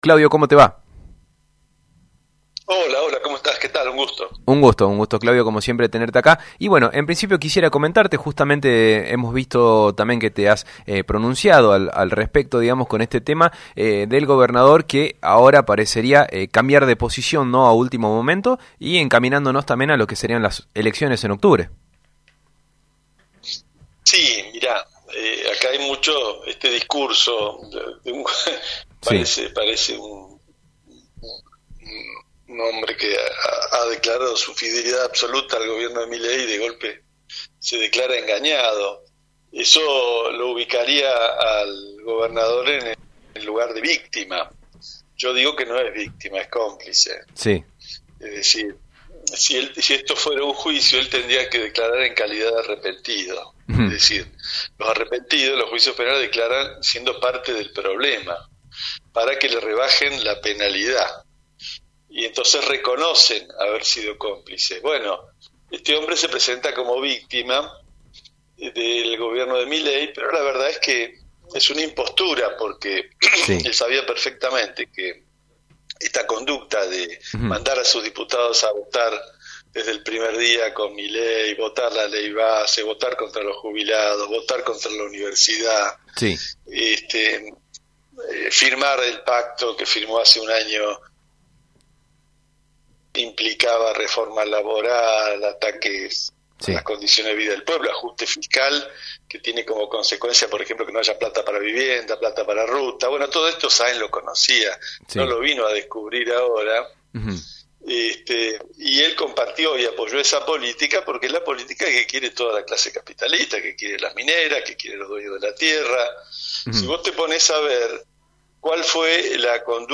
LA PALABRA DEL ENTREVISTADO, CLAUDIO DEL PLÁ
Claudio del Plá, entrevistado en el programa Máxima Mañana.